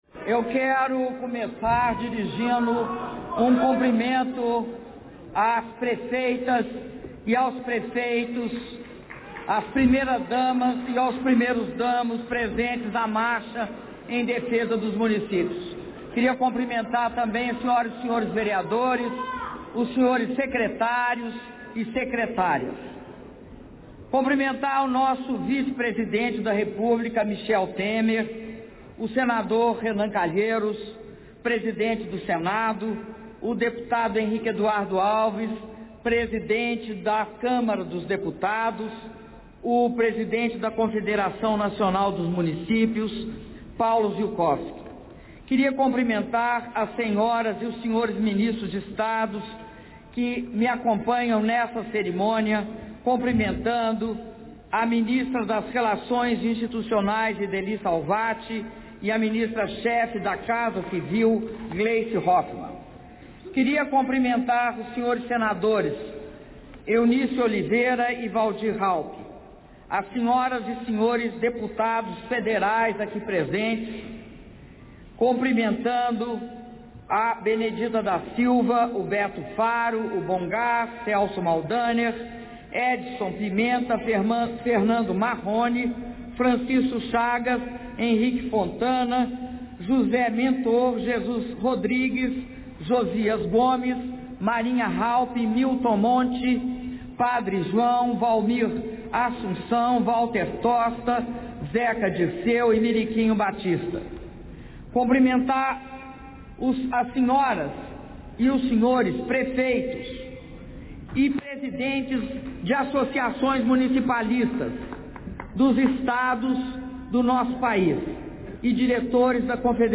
Discurso da Presidenta da República, Dilma Rousseff, durante cerimônia da XVI Marcha à Brasília em Defesa dos Municípios